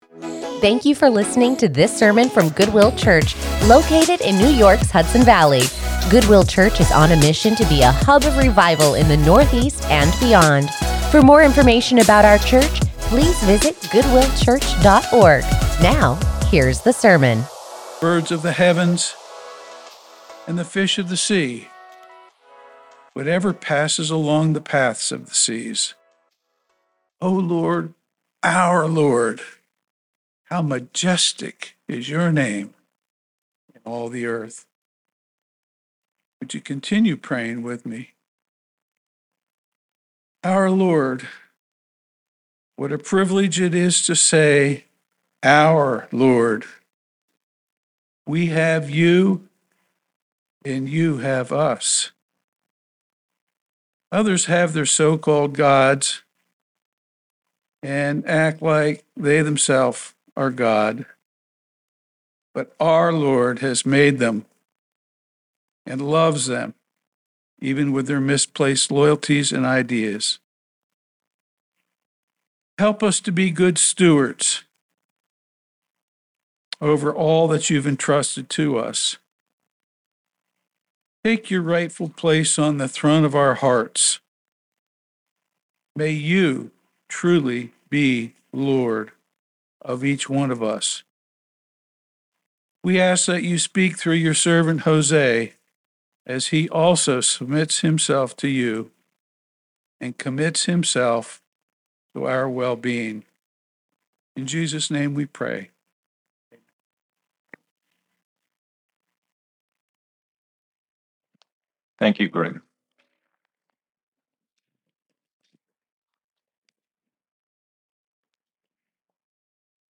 Join us in studying God's Word as we take a break from our sermon series with this sermon, “Majestic and Mindful” | Psalm 8